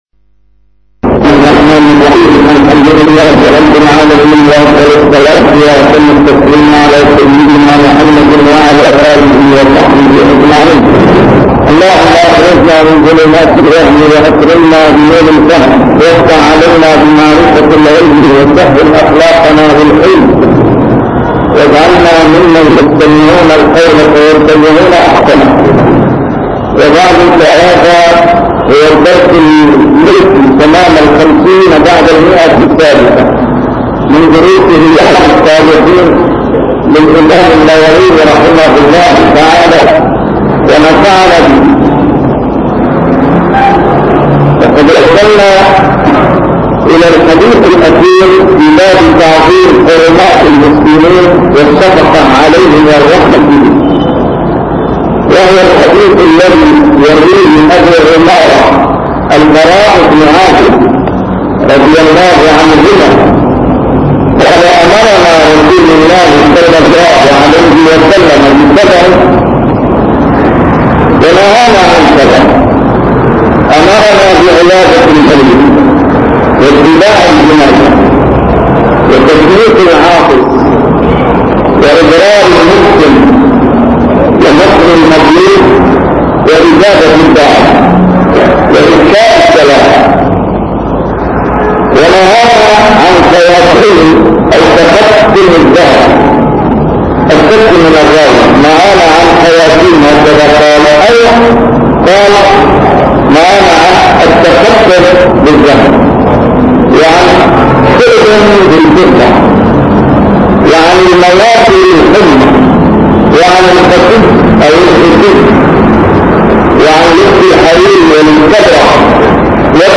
A MARTYR SCHOLAR: IMAM MUHAMMAD SAEED RAMADAN AL-BOUTI - الدروس العلمية - شرح كتاب رياض الصالحين - 350- شرح رياض الصالحين: تعظيم حرمات المسلمين